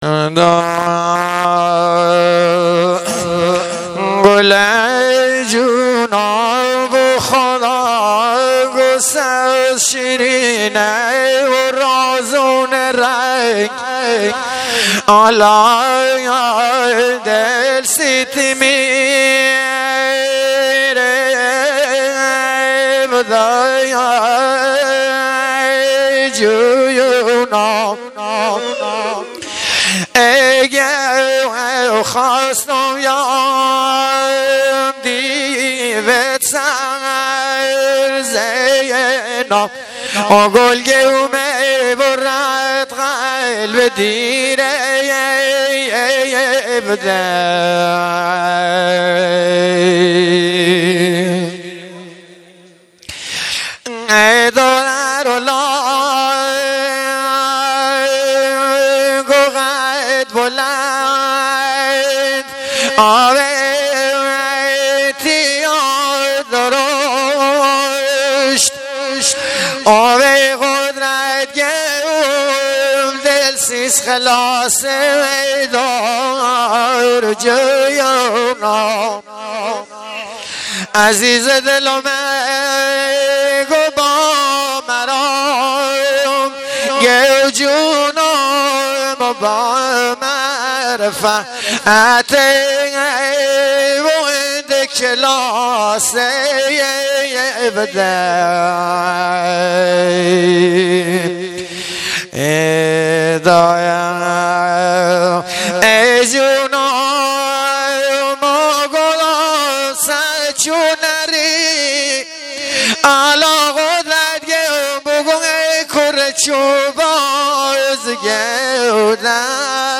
لری > بختیاری
(غمگین)
Lori music